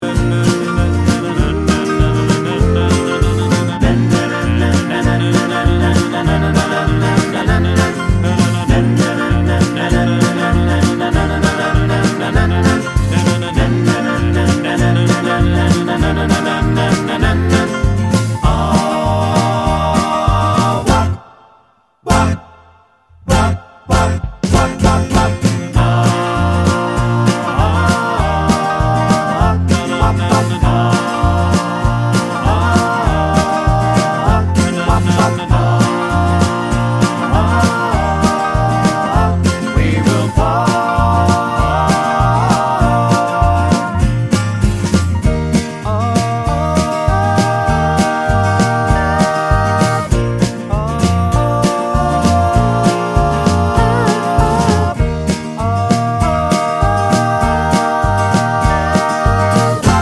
Hybrid Mix Rock 'n' Roll 3:18 Buy £1.50